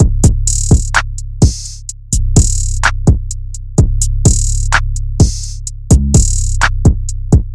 鼓声循环陷阱
Tag: 135 bpm Trap Loops Drum Loops 1.20 MB wav Key : Unknown